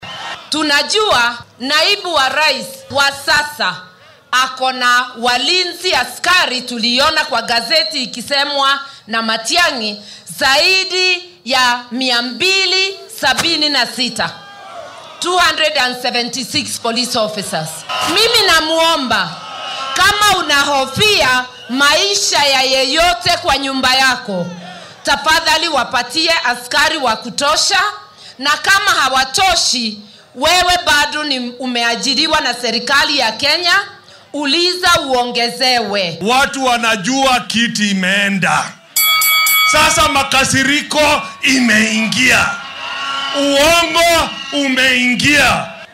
Hadalkan ayaa ka jeedisay isku soo bax siyaasadeed uu isbeheysiga Azimio ku qabtay ismaamullada Uasin Gishu iyo Nandi